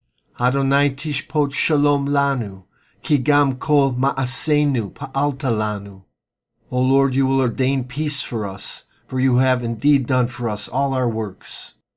Isaiah 26:12 Hebrew Reading (click):